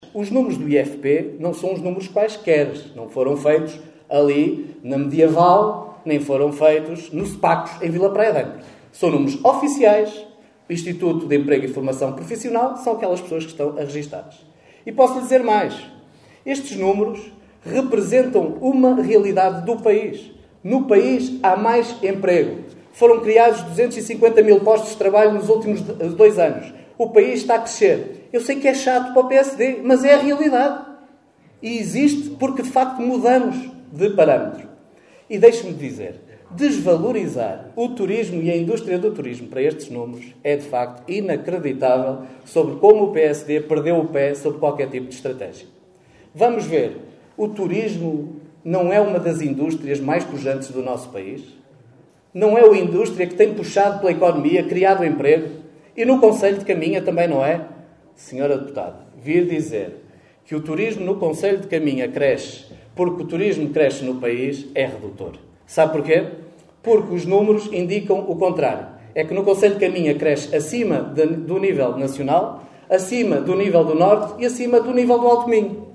Extratos da última Assembleia Municipal de Caminha.